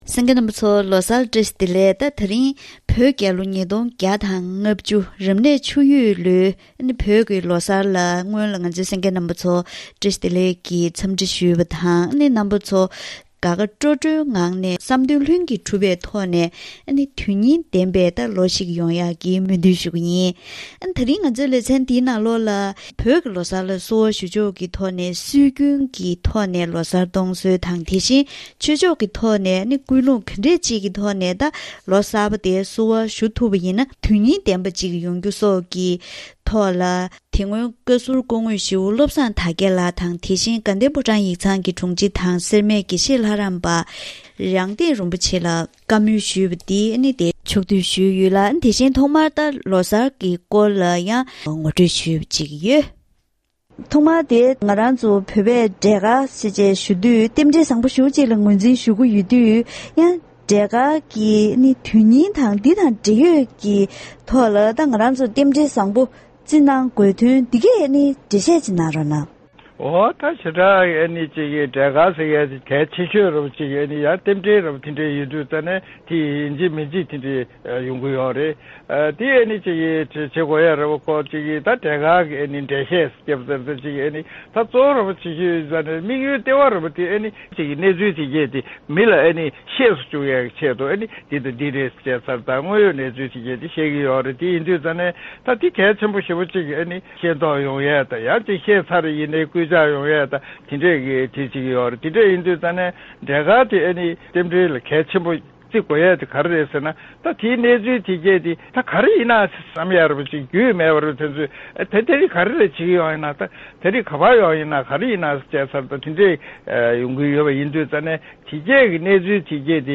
ད་རིང་གི་གཏམ་གླེང་ཞལ་པར་ལེ་ཚན་ནང་བོད་ཆུ་ཡོས་ལོའི་གནམ་ལོ་གསར་བཞད་ཀྱི་སྐབས་དེར་བོད་མི་ཚོས་རང་ཉིད་ཀྱི་སྲོལ་རྒྱུན་དུས་ཆེན་ལོ་གསར་ལ་བསུ་བ་ཞུ་སྟངས་སྐོར་ལོ་རྒྱུས་ཀྱི་གནས་ཚུལ་དང་། དེ་བཞིན་ལོ་གསར་གྱི་གོམས་སྲོལ། བོད་མི་ཚོས་ལོ་གསར་པར་བསུ་བ་ཞུ་བའི་སྐབས་བསམ་བློའི་འཁྱེར་ཕྱོགས་ཀྱི་ཀུན་སློང་སོགས་ཆོས་ཕྱོགས་ནས་བསུ་བ་ཞུ་སྲོལ་སོགས་ཀྱི་གནས་ཚུལ་ཁག་ཅིག་ངོ་སྤྲོད་ཞུས་པ་ཞིག་གསན་རོགས་གནང་།